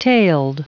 Prononciation du mot tailed en anglais (fichier audio)